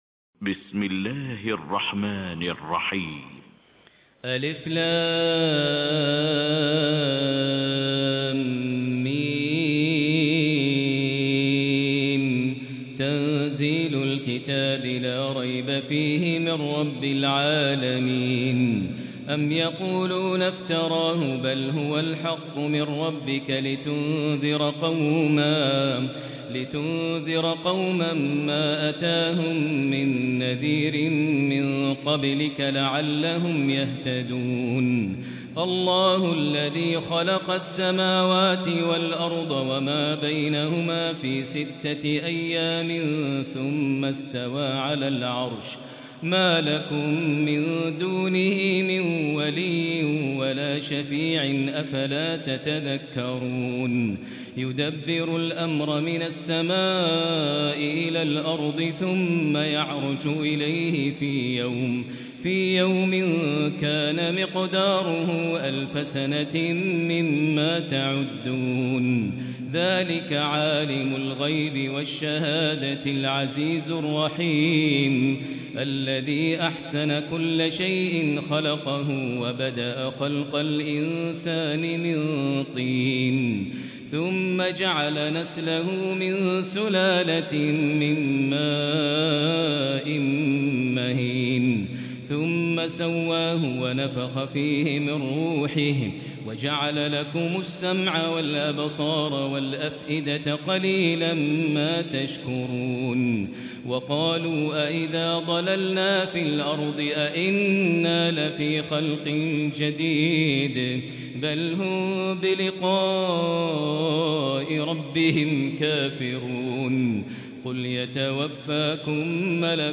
Quran recitations
Tarawih prayer from the holy Mosque